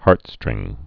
(härtstrĭng)